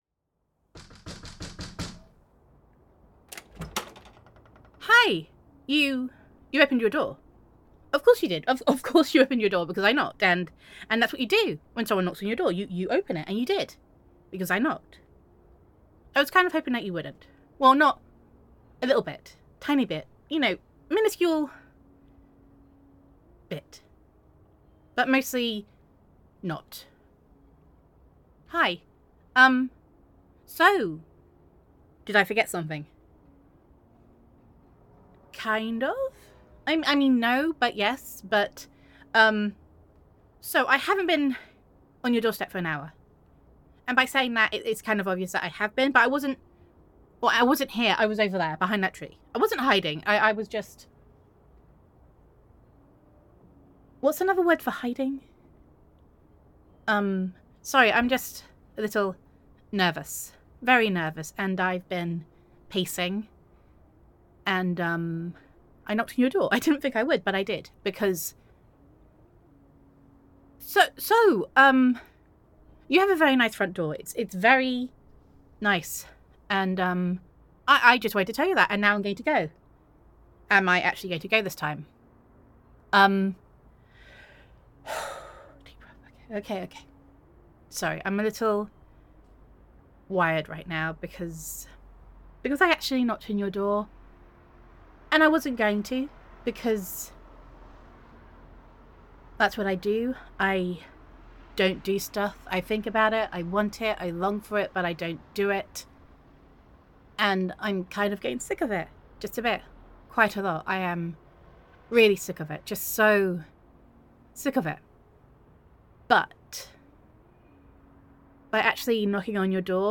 [F4A] Love on Your Doorstep [Best Friend Roleplay]
[Nervous]
[Flustered]